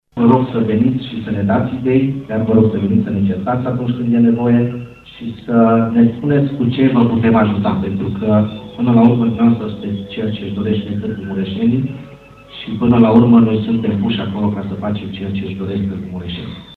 La eveniment, viceprimarul Claudiu Maior a declarat că îi invită pe târgumureșeni la un dialog deschis și sincer.